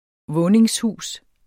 Udtale [ ˈvɔːneŋsˌhuˀs ]